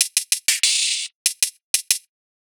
Index of /musicradar/ultimate-hihat-samples/95bpm
UHH_ElectroHatB_95-03.wav